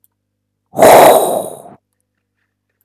fireball.mp3